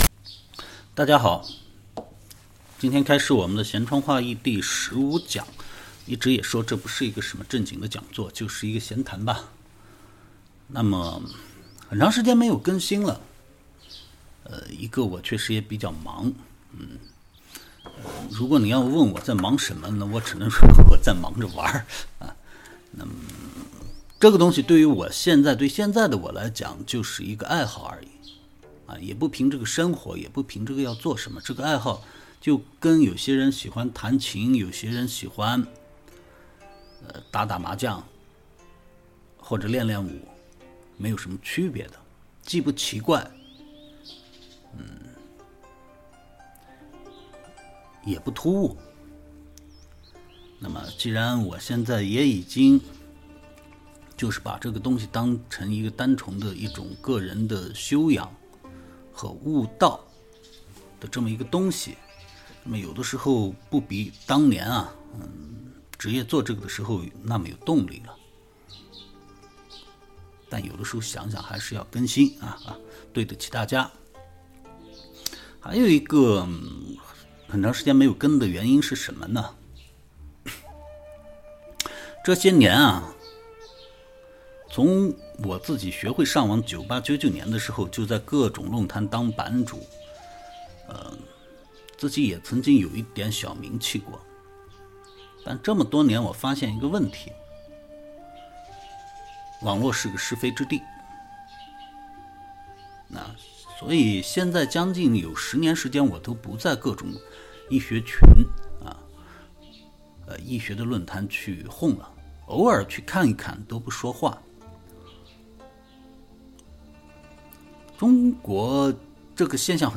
闲窗话易第十五讲背景音版.mp3